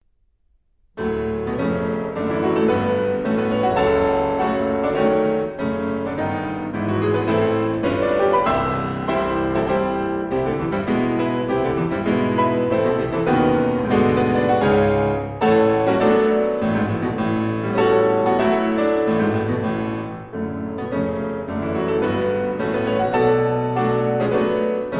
in C sharp minor: Allegro con fuoco